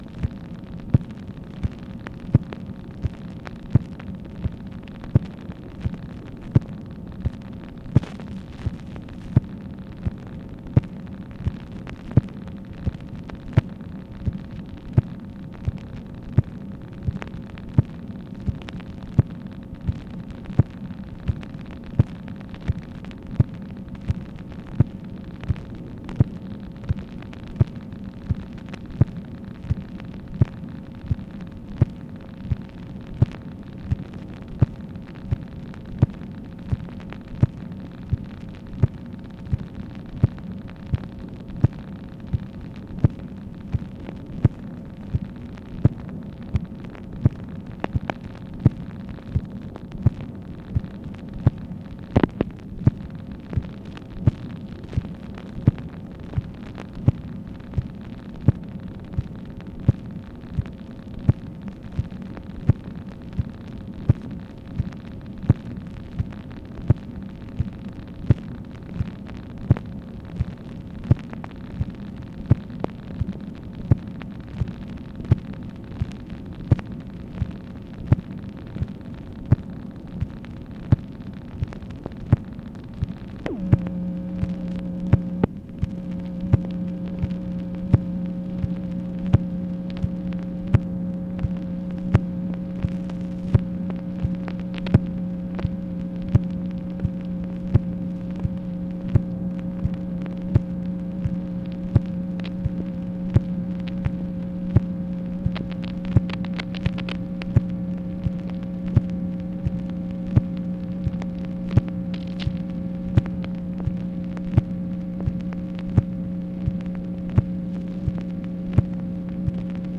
MACHINE NOISE, May 3, 1965
Secret White House Tapes | Lyndon B. Johnson Presidency